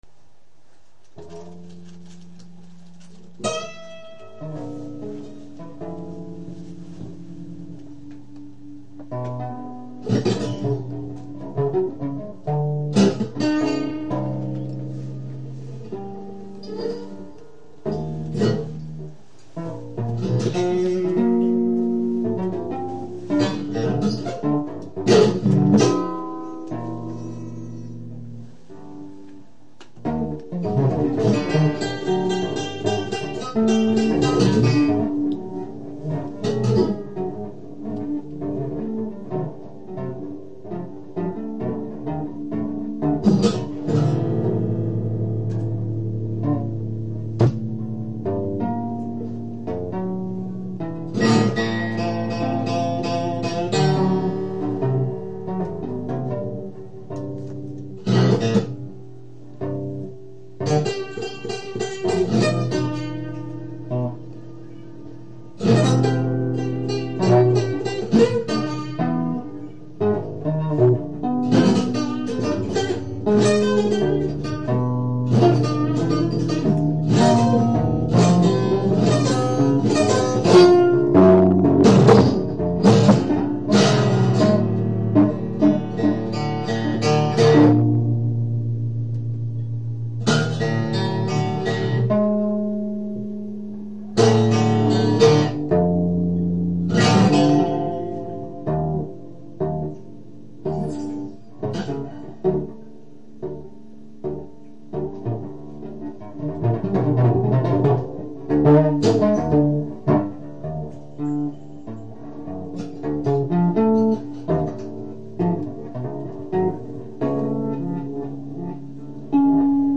吉祥寺 GRID605
4個のカセットテープレコーダーを使ったお琴のように弾くベースと
アコースティック・ギターとの演奏
レコーダーだけの演奏となる
レコーダーの位置によって爆発的に音が変化
テルミンのように演奏したりする